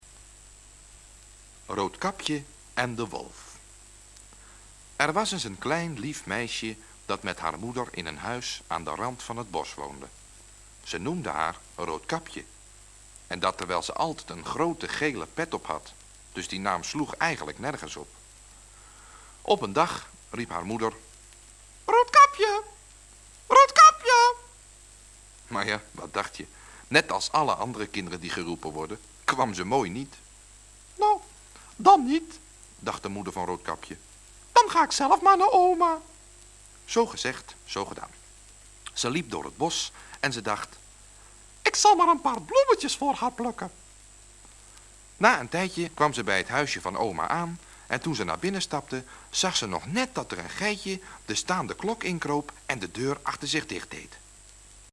Vertelling in een bewerking van Carlo v.d. Vegt